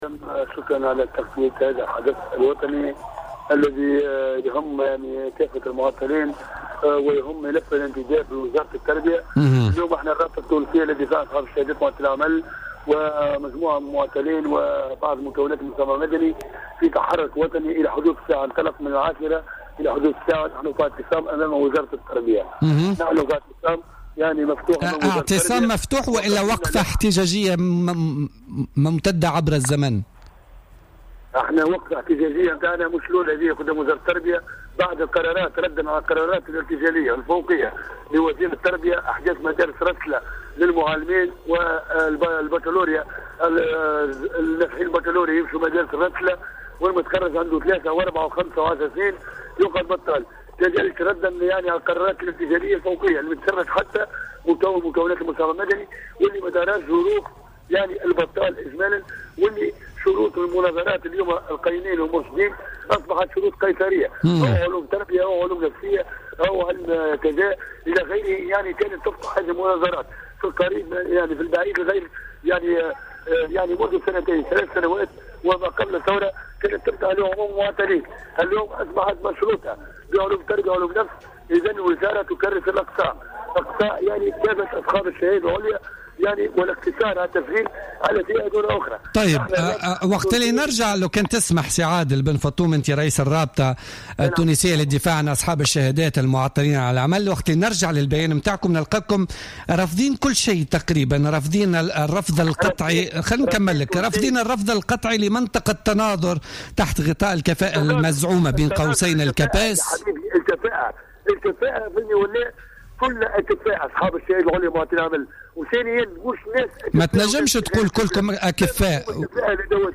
في تصريح للجوهرة أف أم في برنامج بوليتكا لليوم